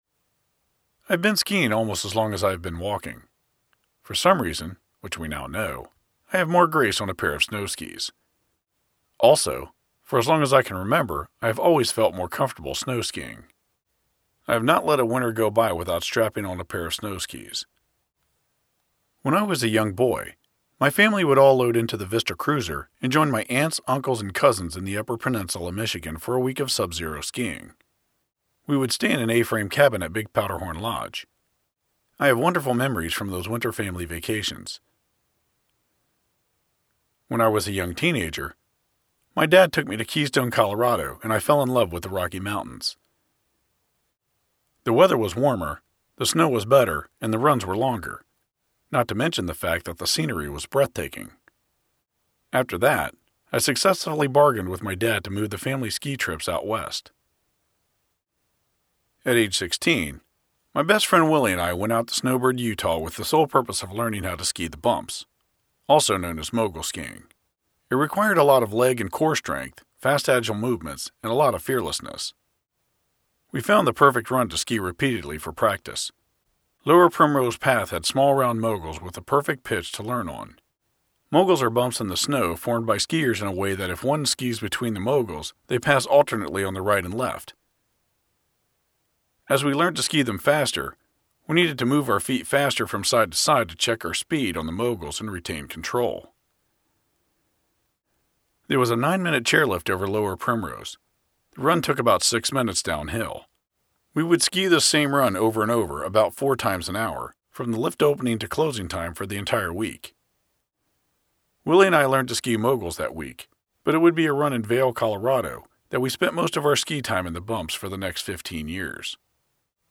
Here is a sampling of my audiobook narration projects and convenient links to them.